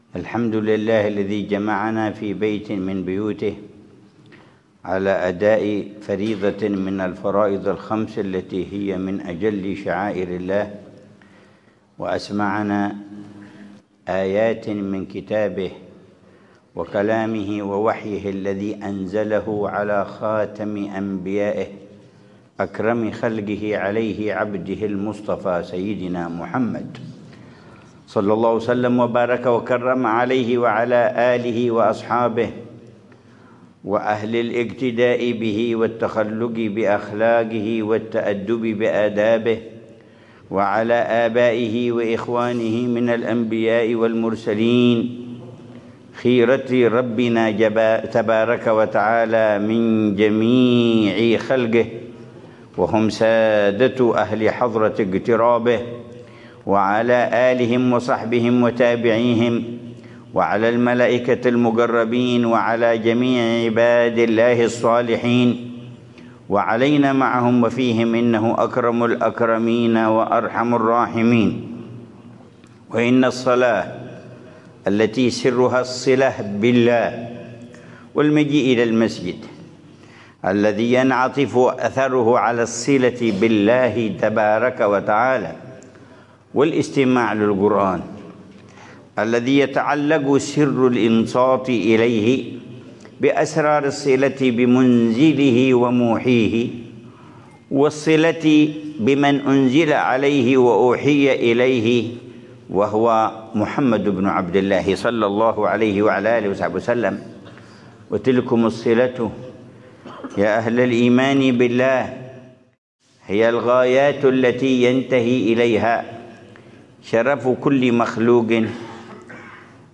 محاضرة للعلامة الحبيب عمر بن حفيظ، في مسجد الصحابي الجليل جابر بن عبد الله الأنصاري، مدينة عمّان، الأردن، ليلة الخميس 19 جمادى الأولى 1446هـ